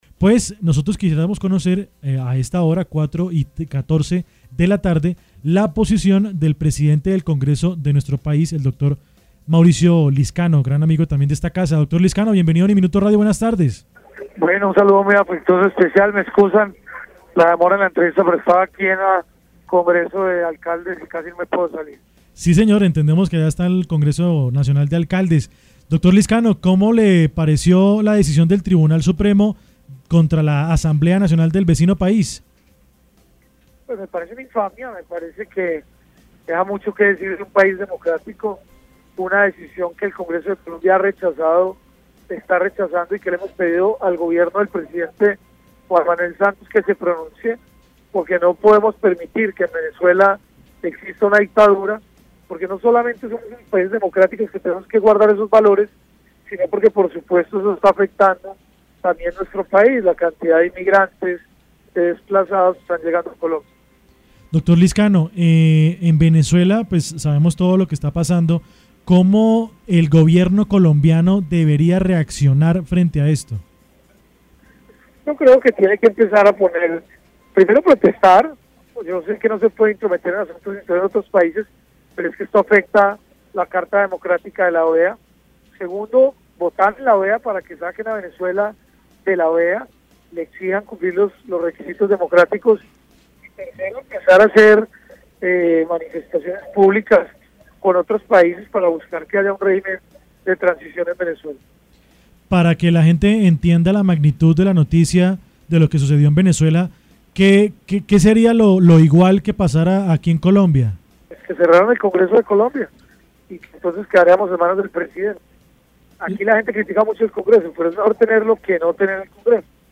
En diálogo con UNIMINUTO Radio estuvo Mauricio Lizcano, presidente del Congreso de la República quien criticó la medida tomada por parte del Tribunal Supremo contra la Asamblea Nacional de Venezuela.